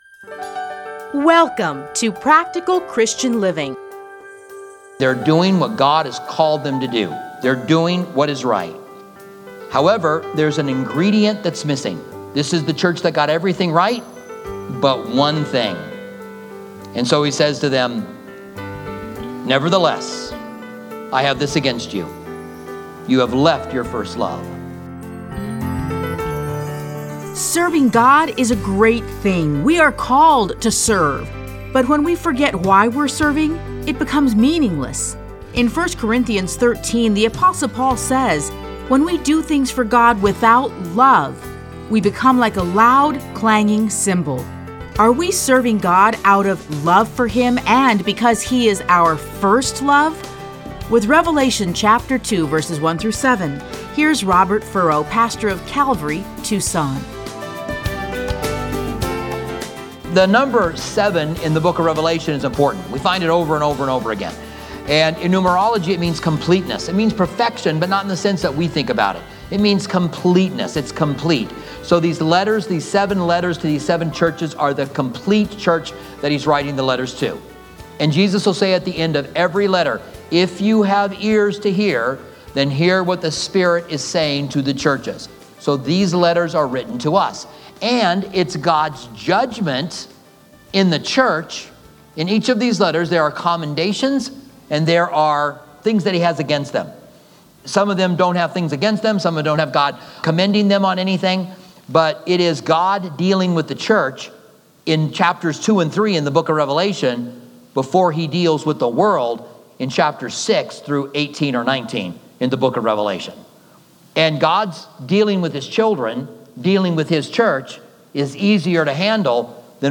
Practical Christian Living. Listen to a teaching from Revelation 2:1-7.